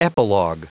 Prononciation du mot epilog en anglais (fichier audio)
Prononciation du mot : epilog